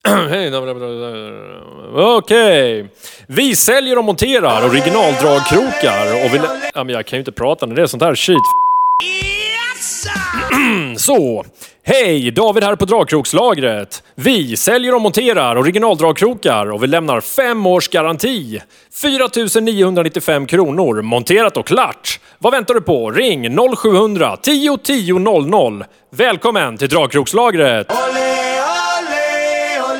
Dragkrok reklam mix megapol radiospot 2016
Tryck här för att lyssna på radioreklamen